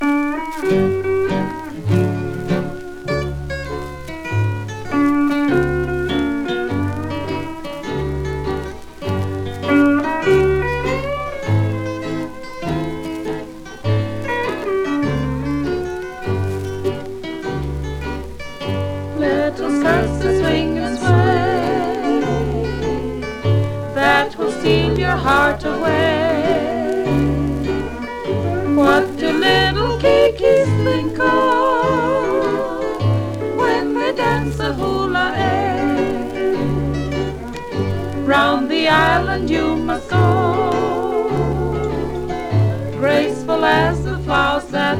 World, Hawaii　USA　12inchレコード　33rpm　Mono